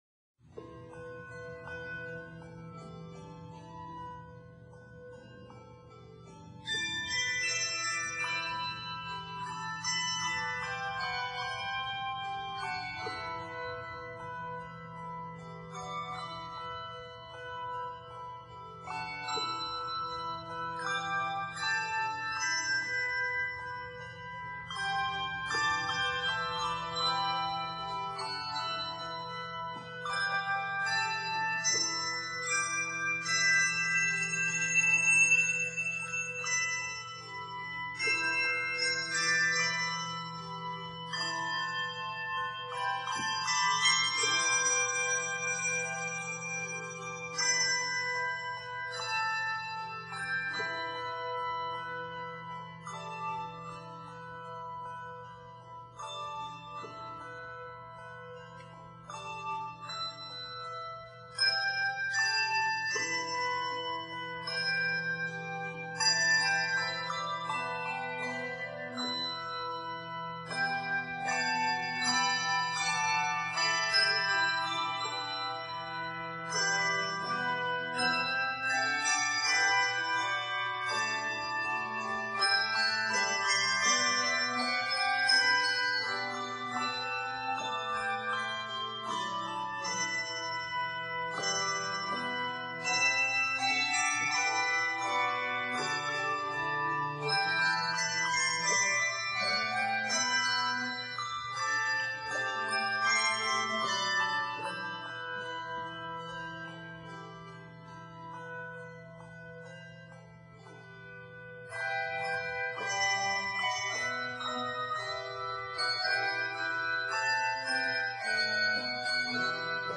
Shape note tunes